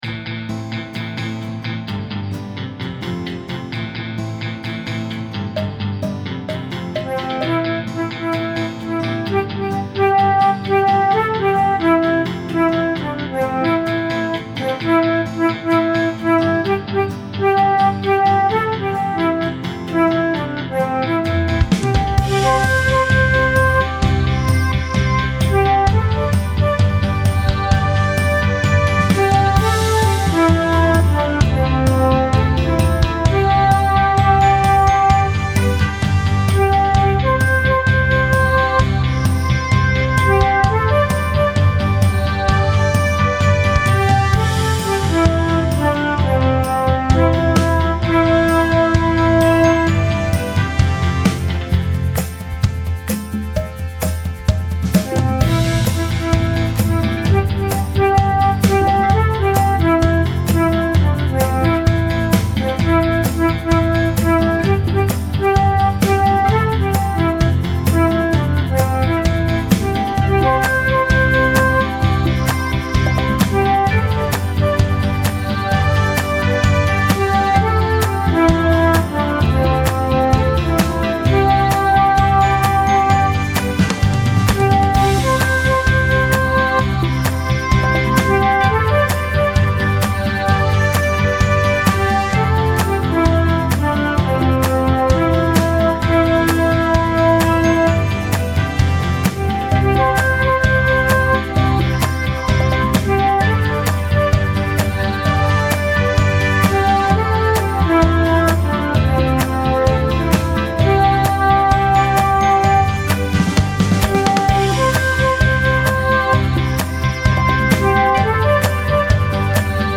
mp3 backing